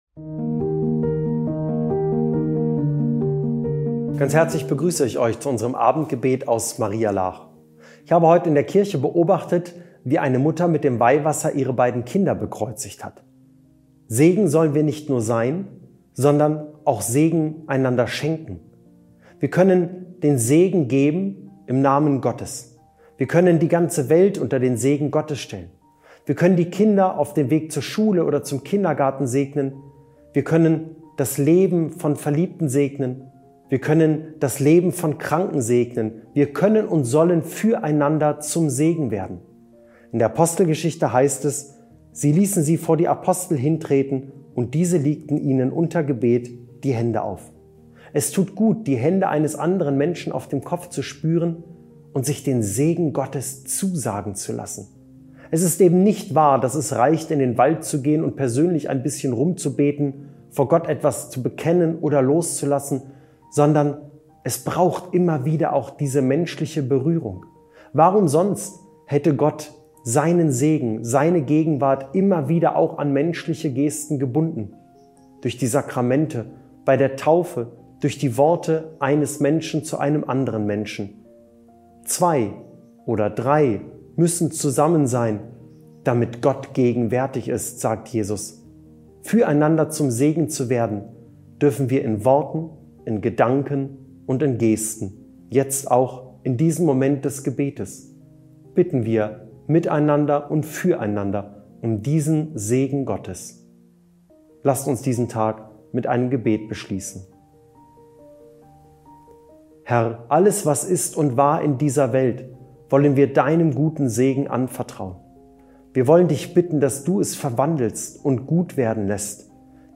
Abendgebet